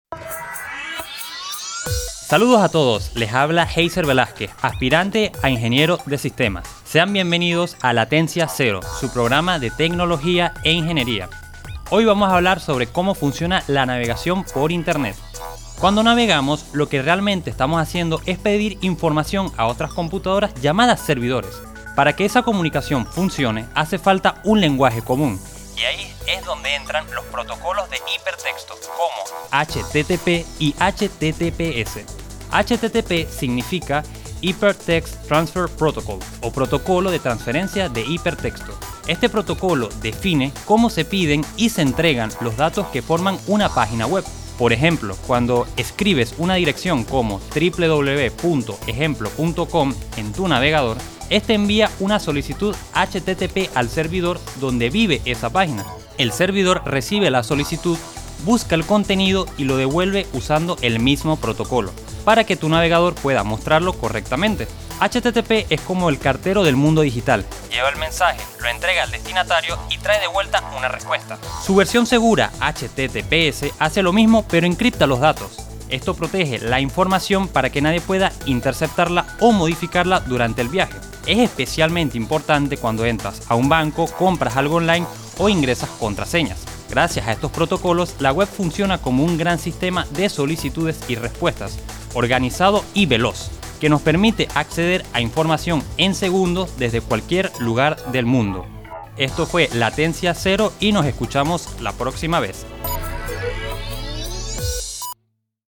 Cada episodio explora sistemas electrónicos y sus invenciones, desde redes digitales hasta algoritmos que mueven el mundo. Con ejemplos cotidianos, humor y entrevistas a expertos, este espacio conecta la teoría con la vida diaria.
Estudiantes de la carrera de Ingeniería de Sistemas